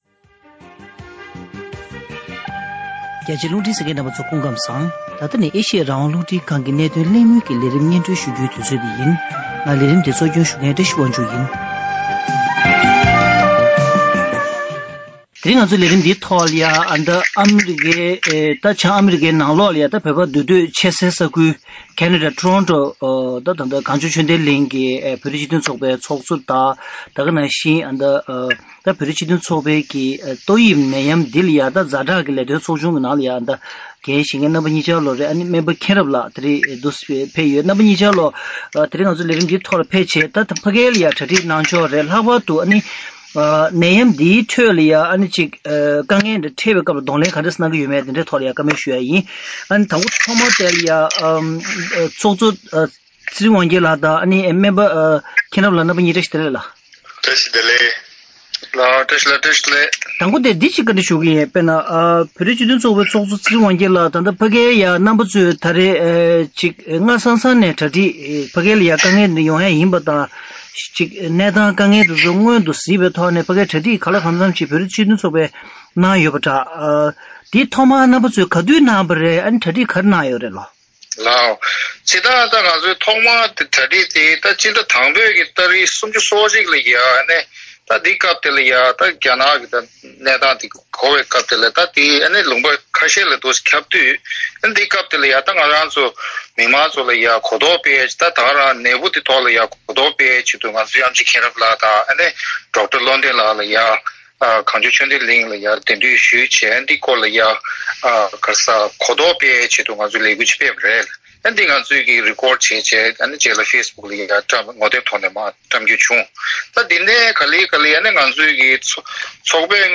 ཁེ་ན་ཌ་ཊོ་རོན་ཊོ་ཁུལ་དུ་བོད་མི་ ༥༠ སྐོར་ཞིག་ལ་ཏོག་དབྱིབས་ནད་འབུ་འགོས་པའི་དྭོགས་གཞི་བྱུང་བའི་ཐད་གླེང་མོལ་ཞུས་པ།